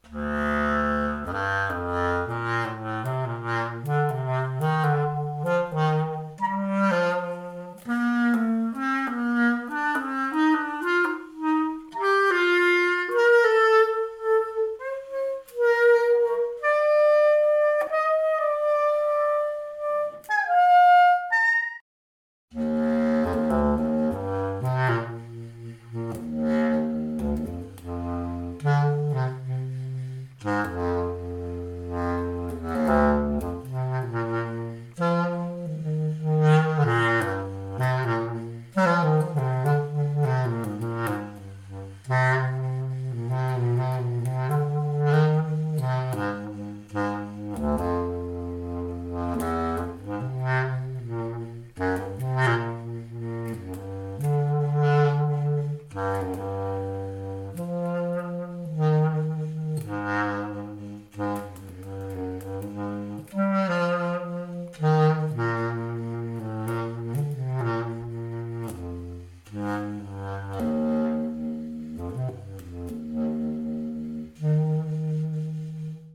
Letzter Stand der Entwicklung: Eine extrem kompakte Baßklarinette